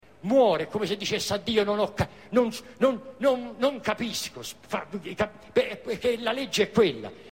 tartagliare.mp3